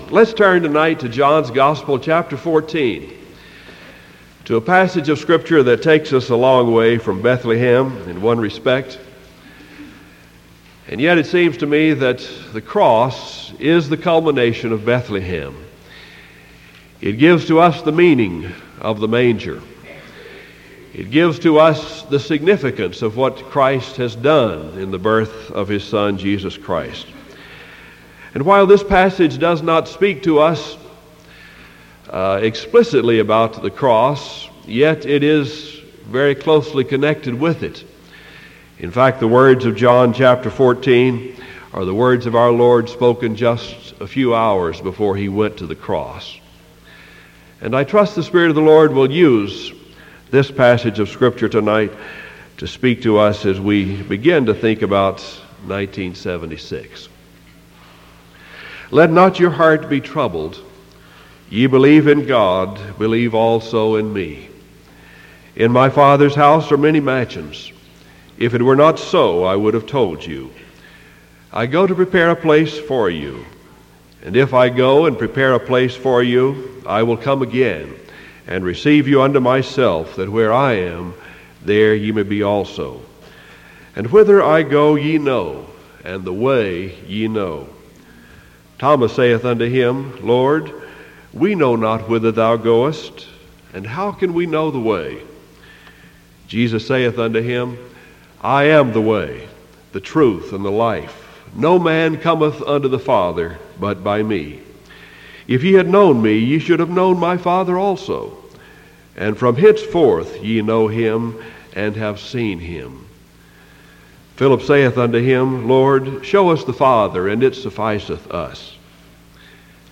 Sermon December 28th 1975 PM